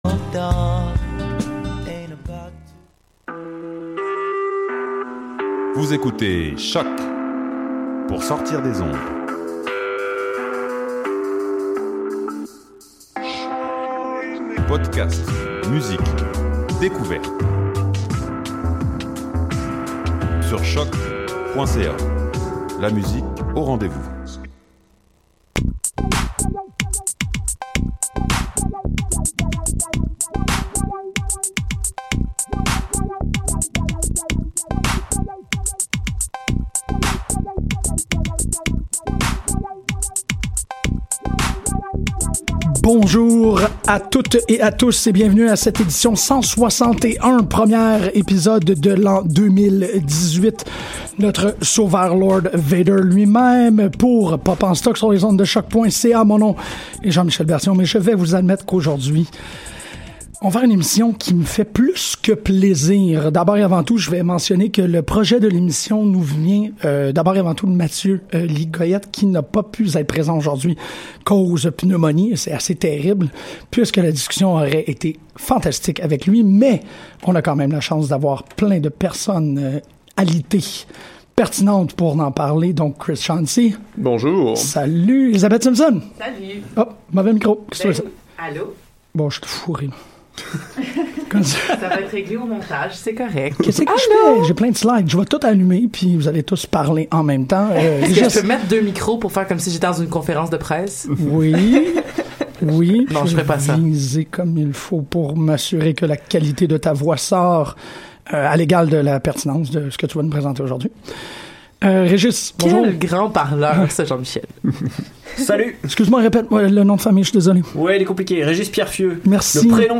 On ouvre l'année avec une discussion (non pas un débat) sur THE LAST JEDI. Pour ou contre, insulté ou exhalté, le huitième opus de la saga de Star Wars ne laisse personne indifférent.